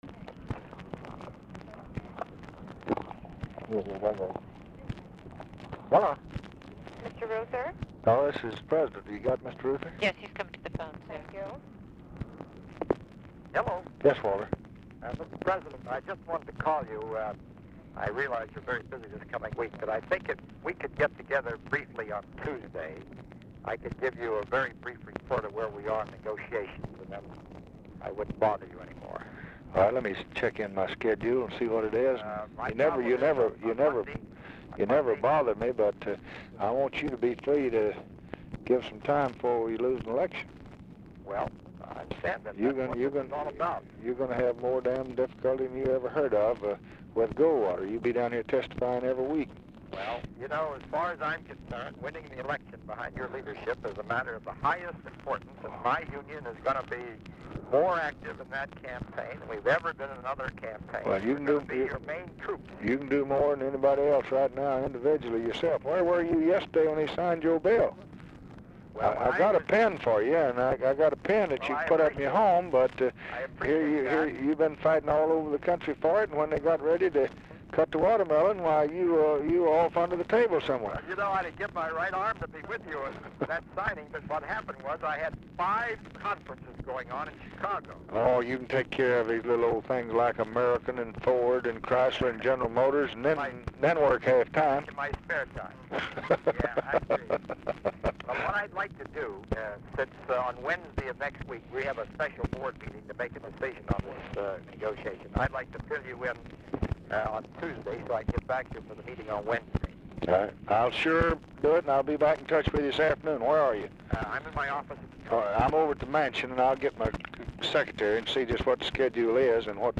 Title Telephone conversation # 5099, sound recording, LBJ and WALTER REUTHER, 8/21/1964, 3:50PM?
Format Dictation belt
Location Of Speaker 1 Mansion, White House, Washington, DC